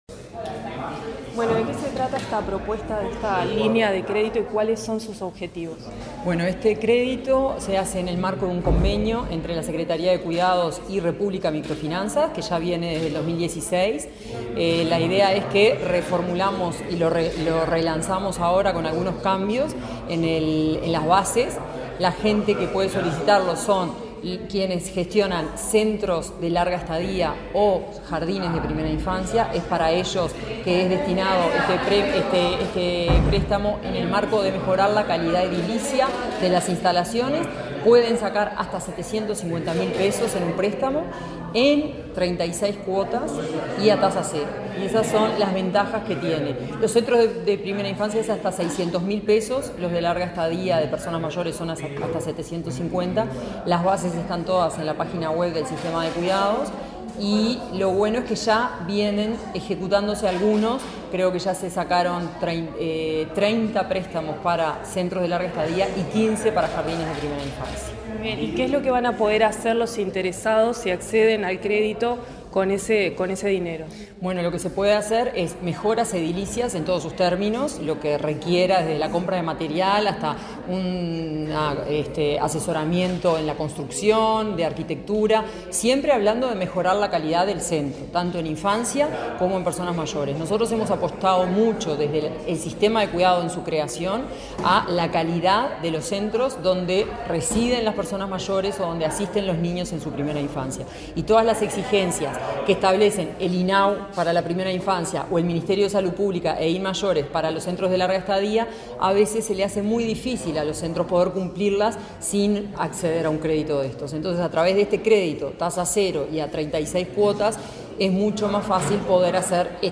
Entrevista a la directora de Cuidados del Mides, Florencia Krall
La directora de Cuidados del Ministerio de Desarrollo Social (Mides), Florencia Krall, dialogó con Comunicación Presidencial acerca del relanzamiento